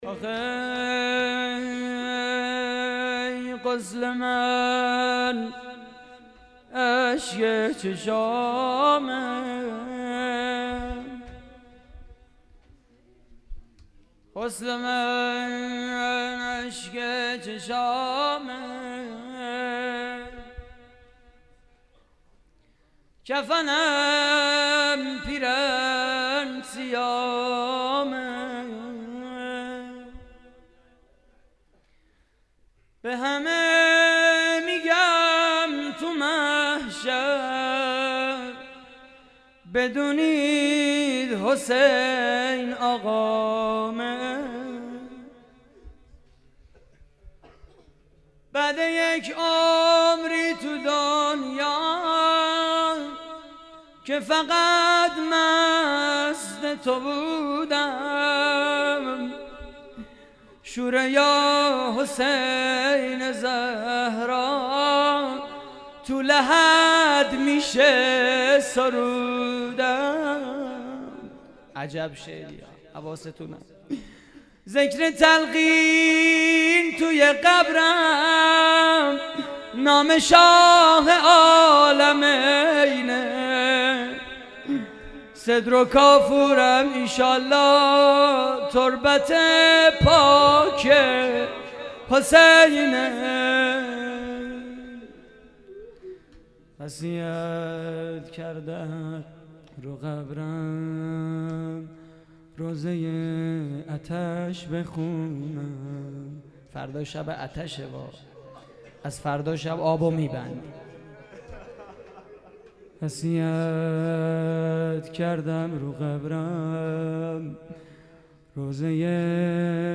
مراسم عزاداری شب ششم ماه محرم / هیئت کانون دانش آموزی امام حسن مجتبی (ع) - نازی‌آباد؛ 20 آذر 89
روضه پایانی: غسل من اشک چشامه